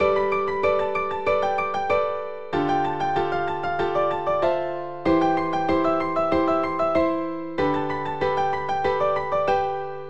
delphis RHYTHMIC CLOCK FX
描述：钟记录在纸箱管
标签： 时钟 DELPHIS FX 壁虱 越来越少 时间